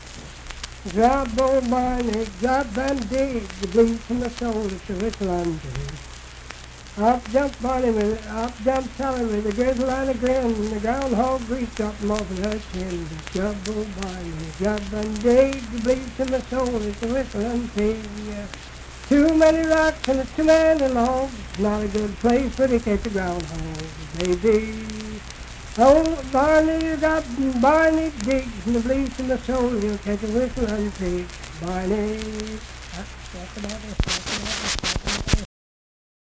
Unaccompanied vocal music
Performed in Ivydale, Clay County, WV.
Dance, Game, and Party Songs
Voice (sung)